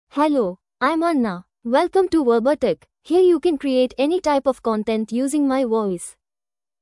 Anna — Female English (India) AI Voice | TTS, Voice Cloning & Video | Verbatik AI
Anna is a female AI voice for English (India).
Voice sample
Listen to Anna's female English voice.
Anna delivers clear pronunciation with authentic India English intonation, making your content sound professionally produced.